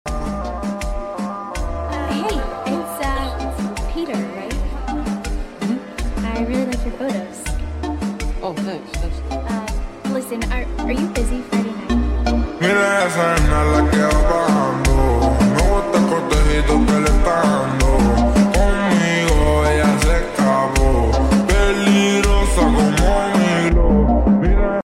(Slowed)